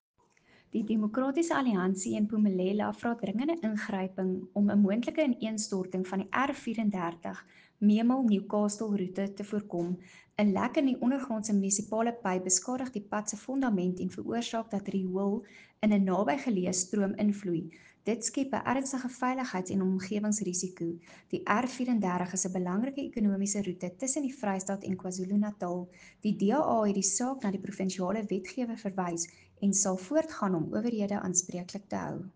Afrikaans soundbite by Cllr Anelia Smit and